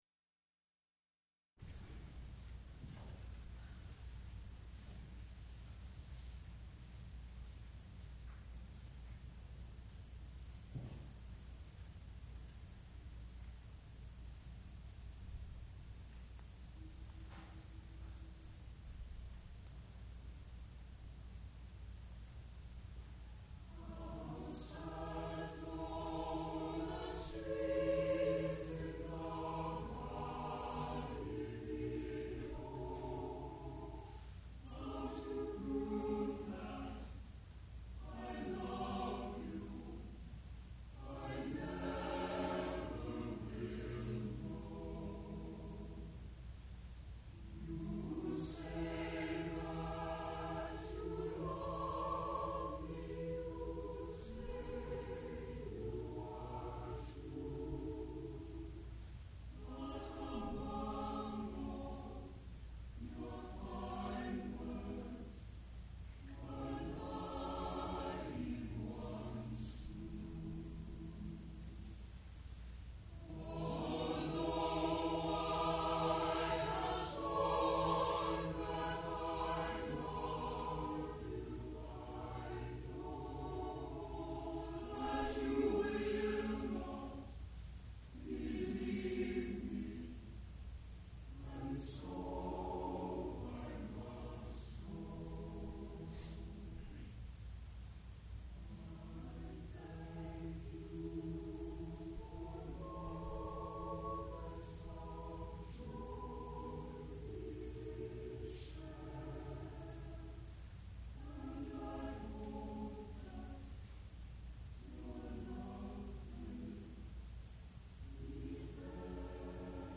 sound recording-musical
classical music
piano